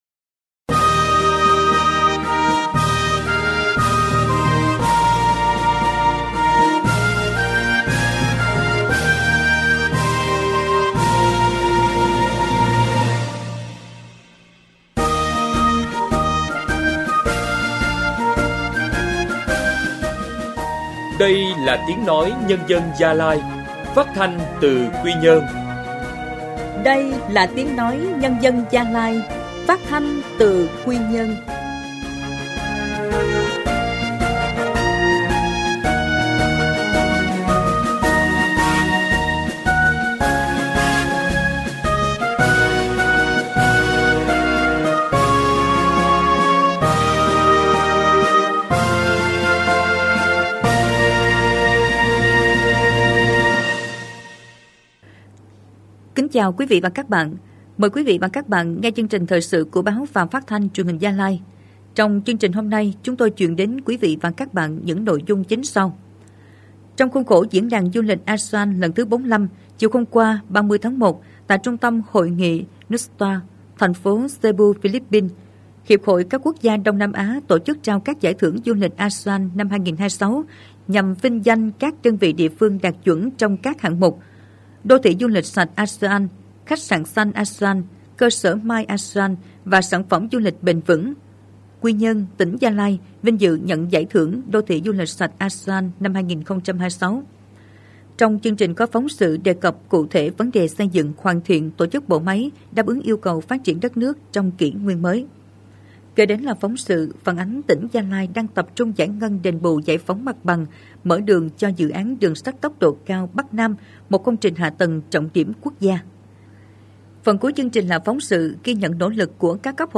Thời sự phát thanh trưa